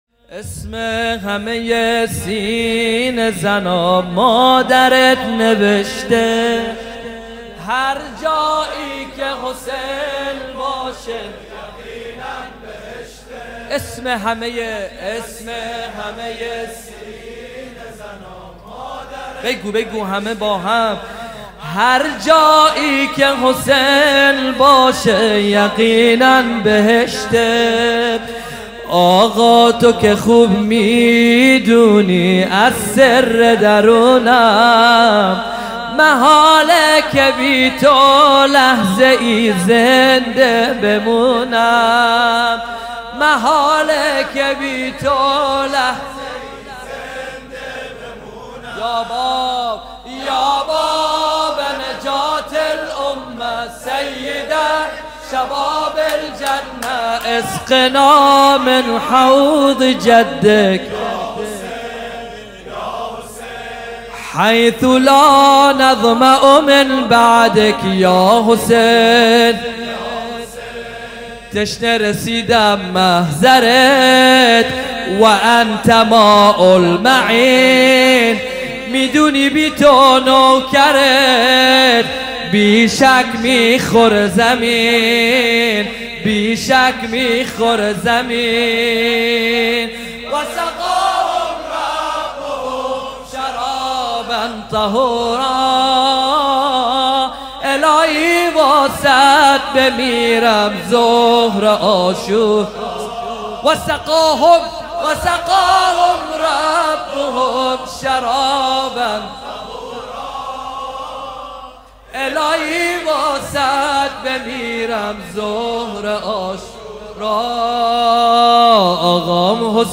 شور (فارسی،عربی) | آقا تو که خوب می‌دونی از سِر درونم
محرم 1439 هجری قمری | هیأت علی اکبر بحرین